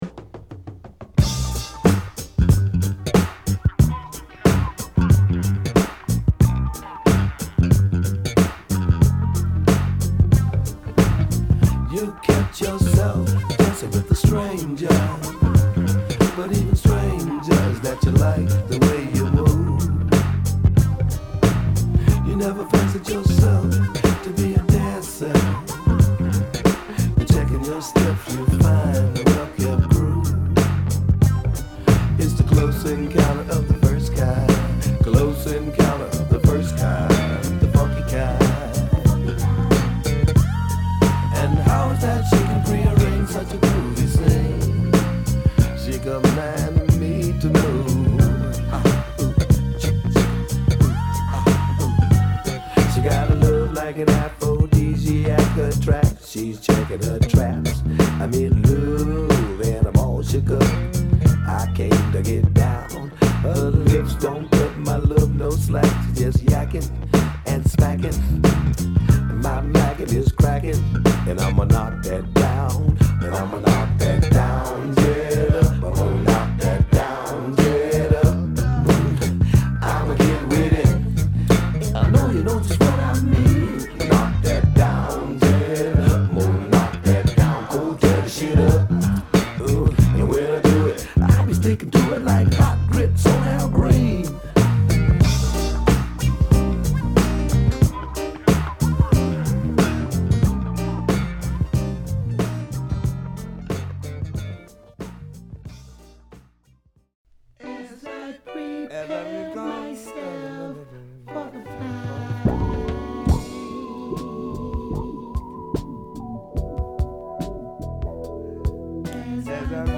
90's , Boogie , Disco , Electro , Funk , Funky
Mellow Groove , P-Funk